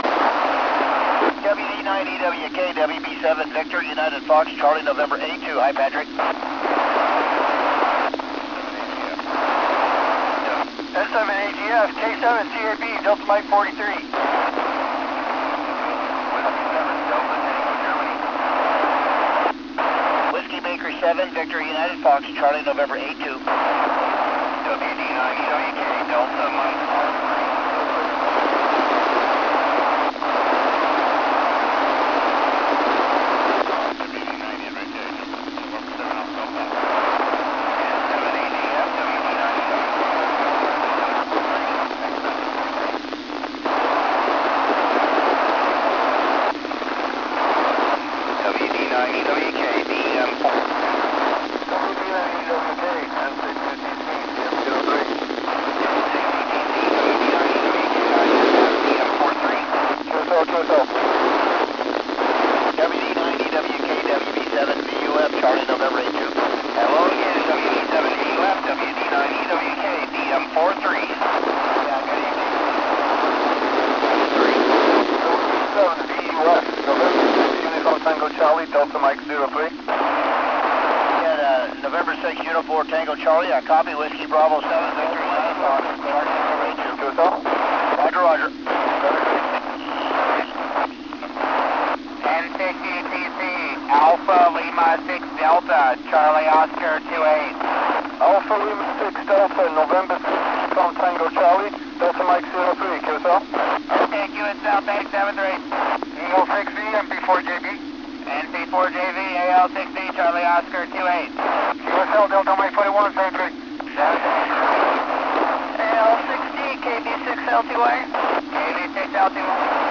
AO-92 First Pass with transponder active
recorded this pass of our newest ham satellite, AO-92, using a Kenwood TH-d74 handheld and a hand held Arrow II satellite antenna.